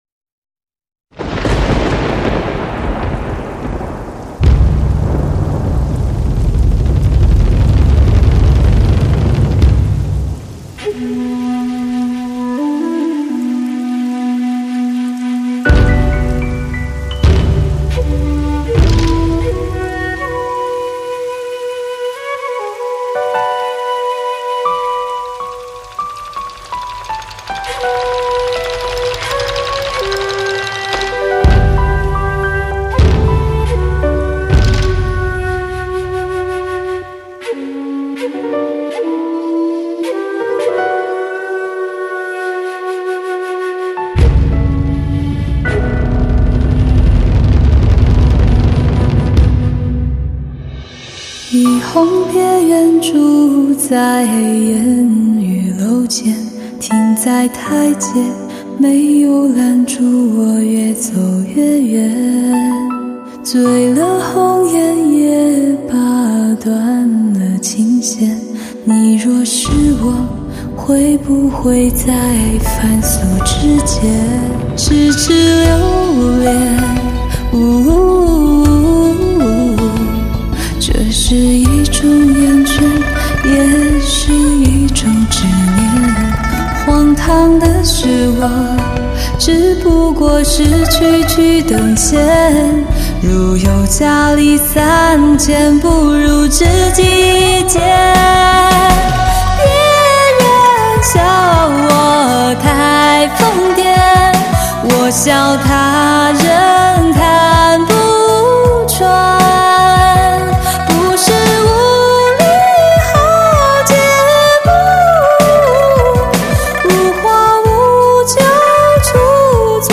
音乐类型: 流行音乐/Pop